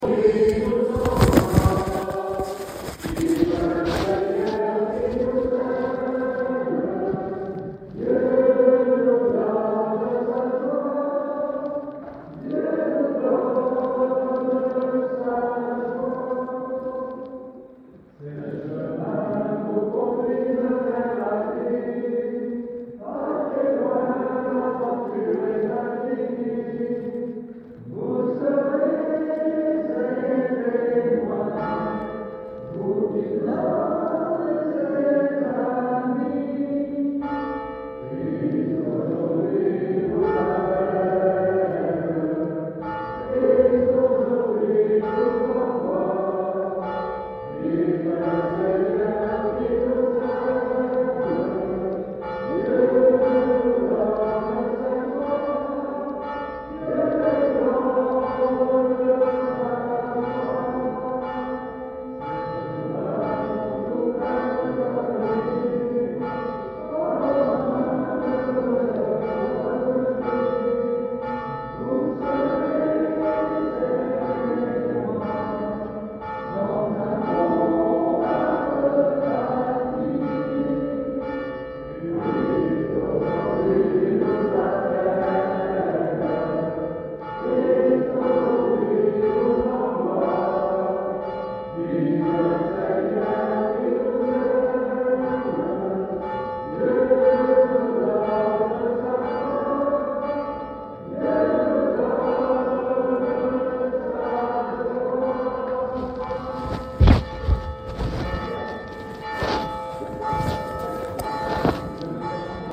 Sons enregistrés lors de la cérémonie :
chant de fin de messe (Dieu nous donne sa joie + sonnerie de cloche)